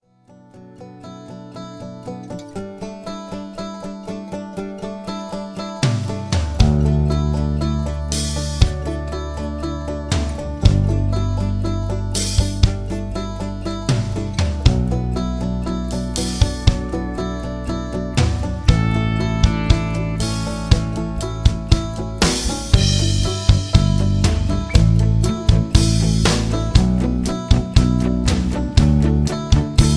Key-D
mp3 backing tracks